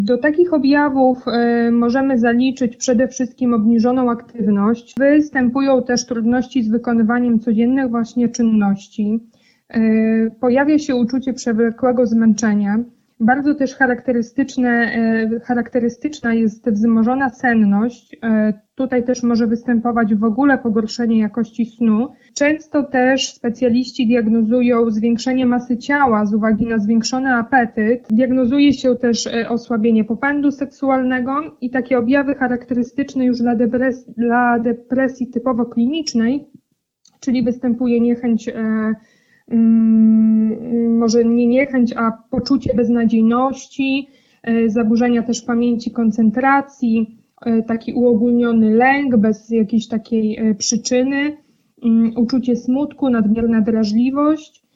Jesień nie musi być smutna – rozmowa z psychologiem